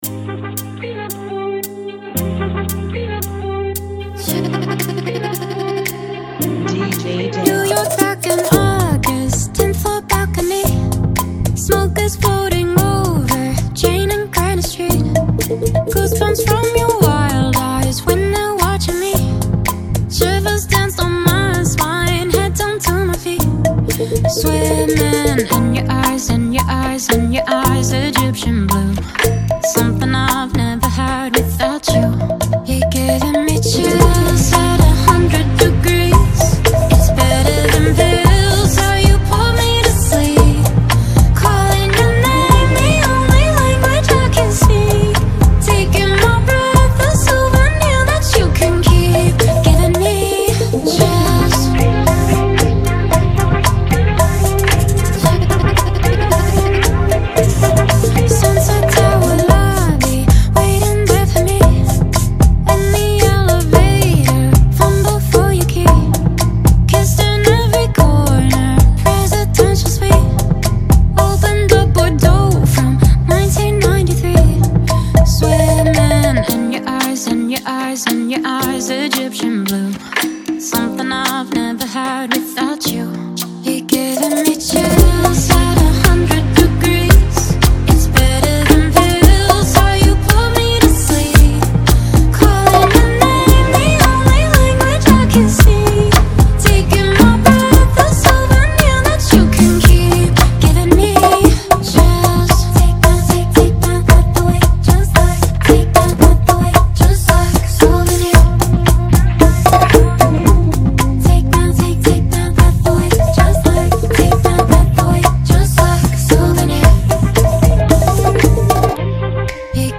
113 BPM
Genre: Bachata Remix